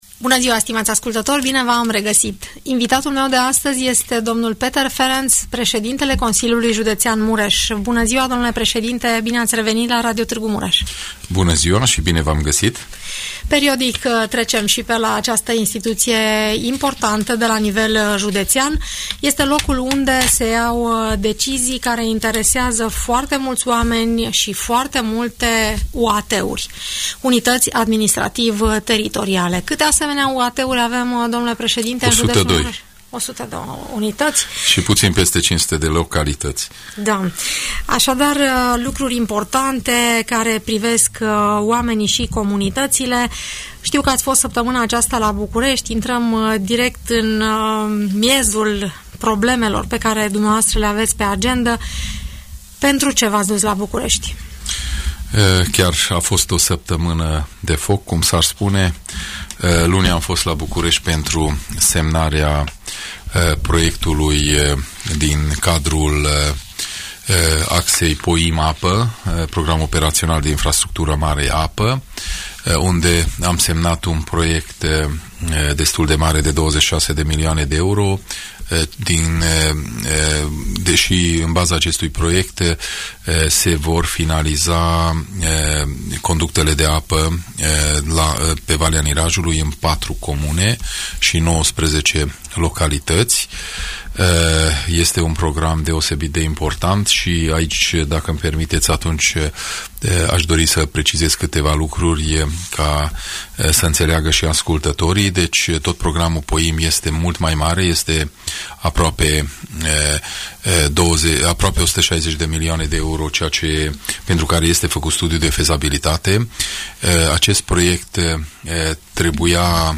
Peter Ferenc, președintele Consiliului Județean Mureș, explică în emisiunea „Părerea ta”, care sunt proiectele în curs de finalizare, dar și cele care urmează să fie demarate la nivel de județ.